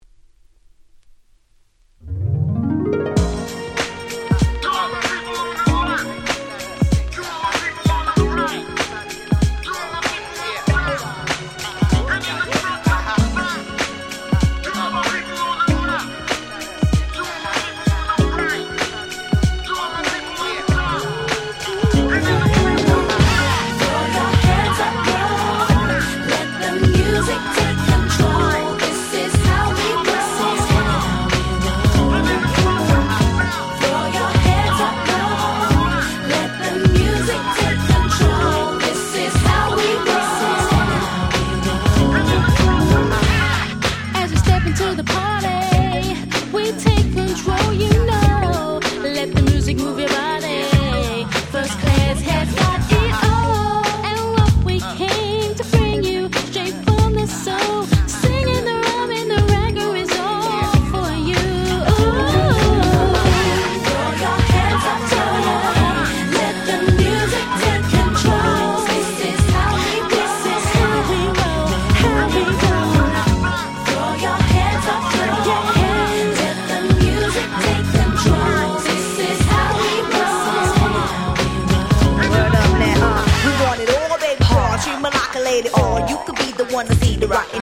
98' Nice EU R&B !!